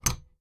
plugpull.wav